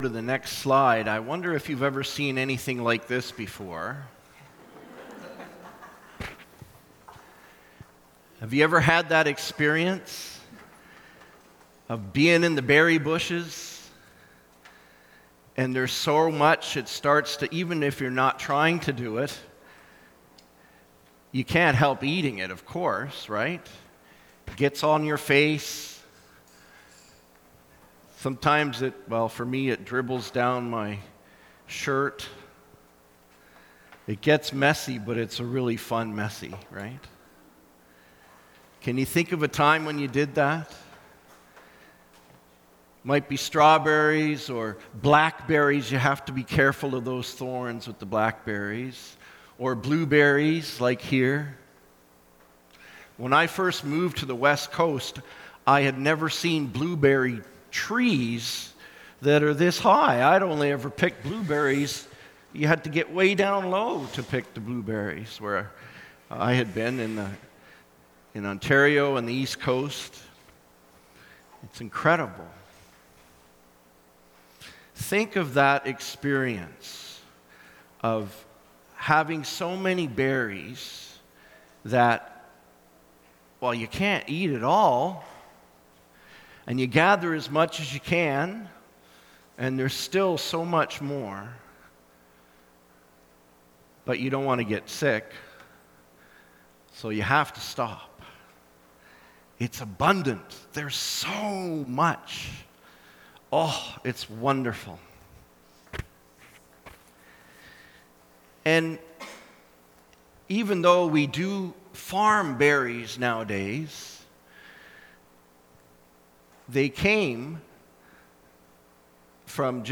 Sermons | Gilmore Park United Church